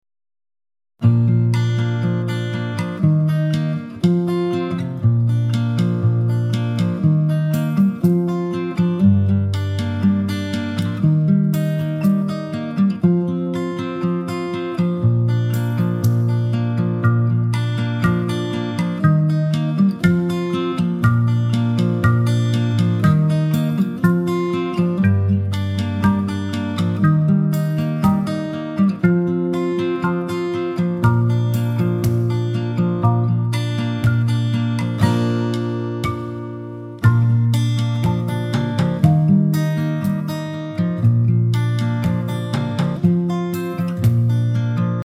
Mp3 Song Download with PDF Lyrics; Mp3 Instrumental Track